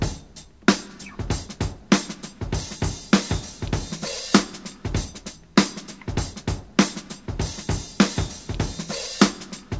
98 Bpm Drum Groove D# Key.wav
Free breakbeat sample - kick tuned to the D# note. Loudest frequency: 2505Hz
98-bpm-drum-groove-d-sharp-key-sVZ.ogg